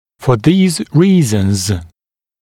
[fə ðiːz ‘riːz(ə)nz][фэ зи:з ‘ри:з(э)нз]по этим причинам